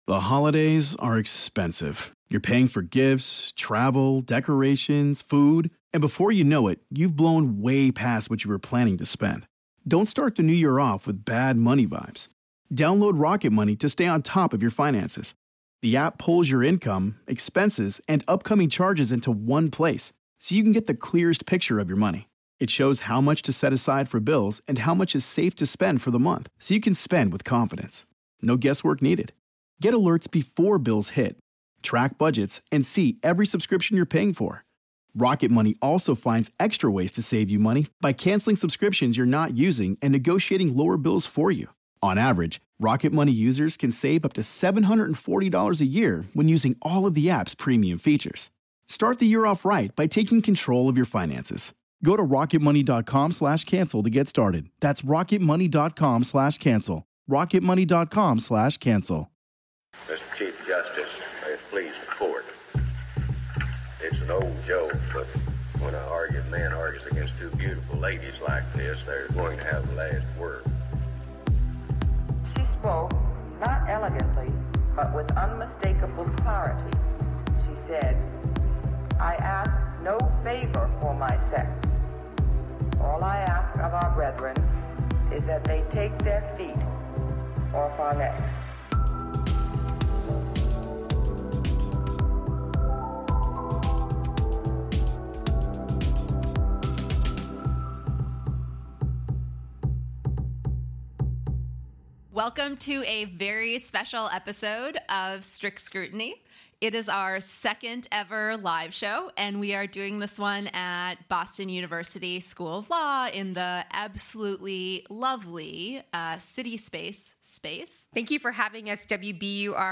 Boston University School of Law put together an amazing live show!
This event was recorded live at WBUR CitySpace in Boston.